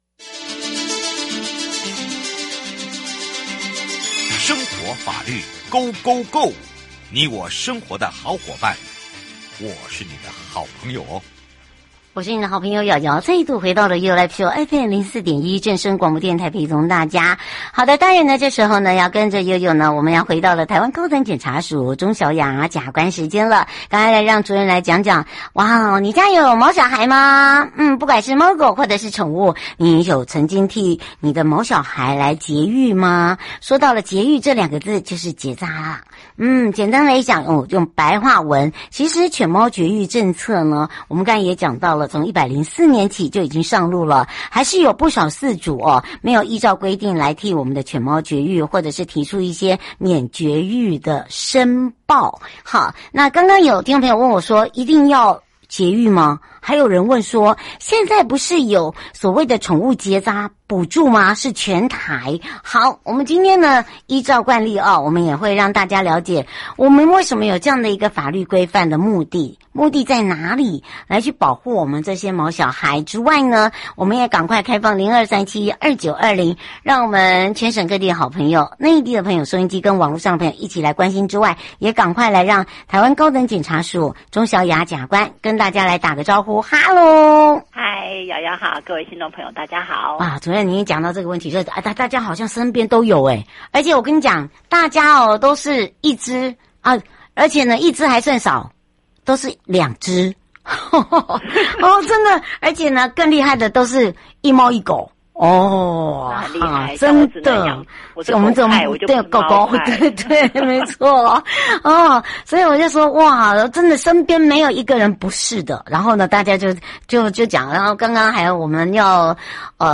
受訪者： 台灣高等檢察署 鍾曉亞檢察官 節目內容： 主題：你替毛小孩節育了嗎？